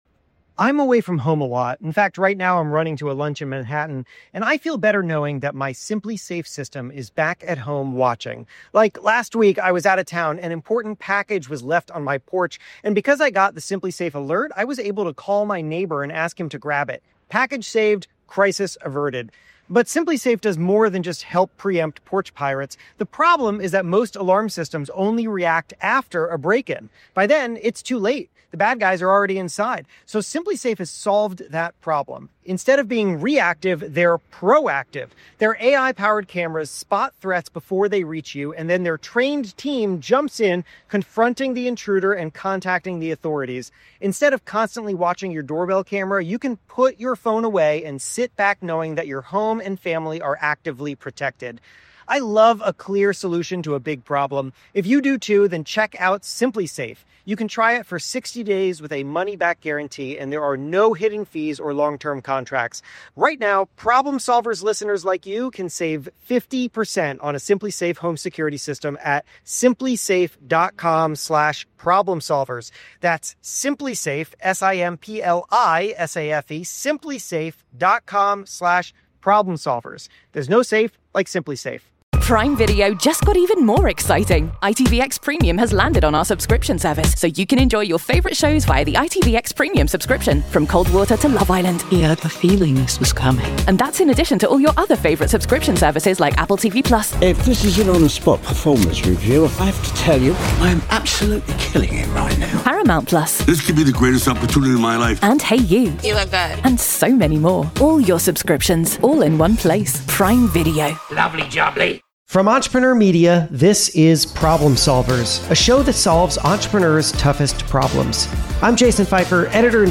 The lead host of Amazon’s NBA on Prime joins the show to share how she earns trust fast, even from the most guarded people in the room.